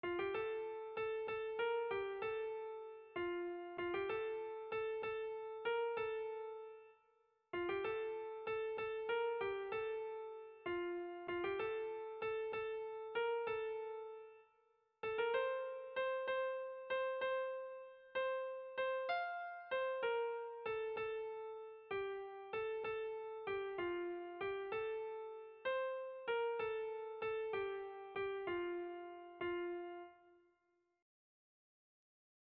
Erromantzea
Zortziko txikia (hg) / Lau puntuko txikia (ip)
AABD